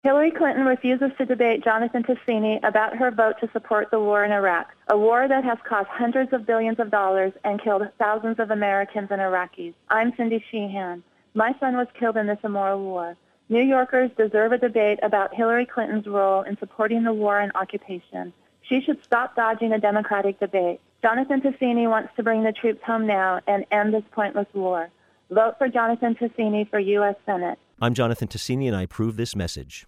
She is hiding from her record, refusing to debate Jonathan in an open forum. We're taking our case to the voters beginning with this radio ad appeal from Cindy Sheehan.